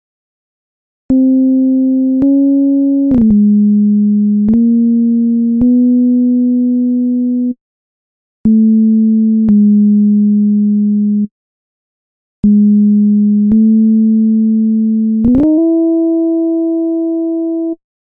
Key written in: C Major
Each recording below is single part only.